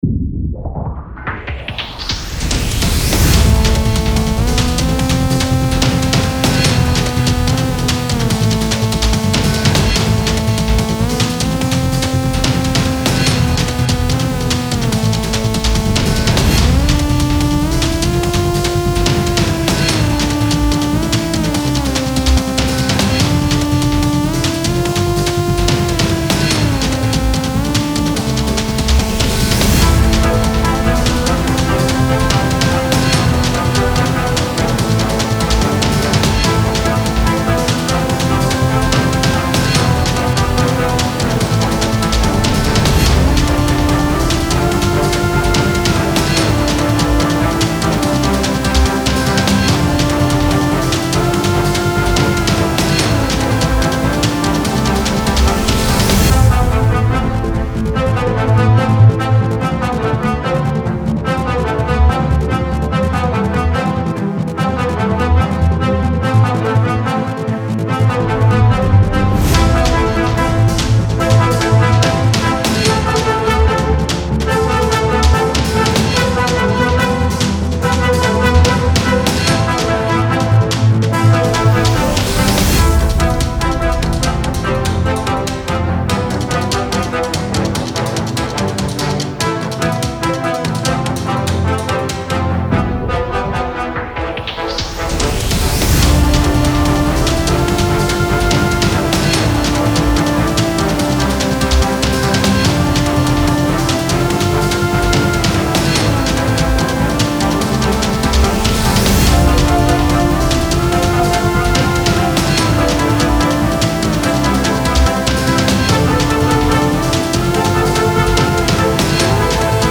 Style Style EDM/Electronic
Mood Mood Driving, Intense
Featured Featured Brass, Drums, Synth
BPM BPM 145